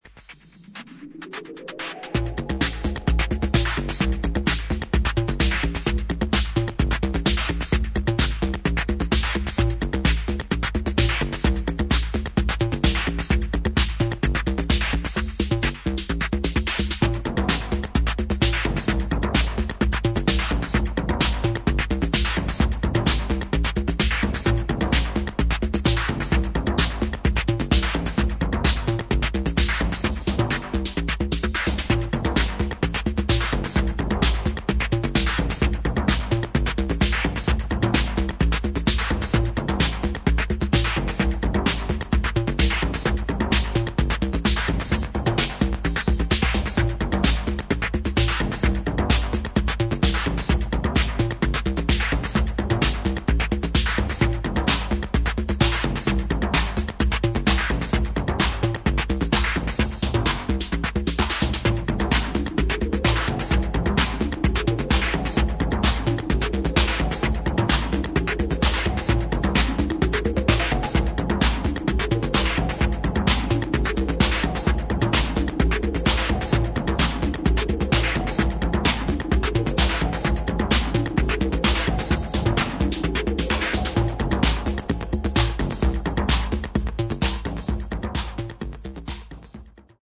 今聴くとレトロ・フューチャーな感触を覚える作品です。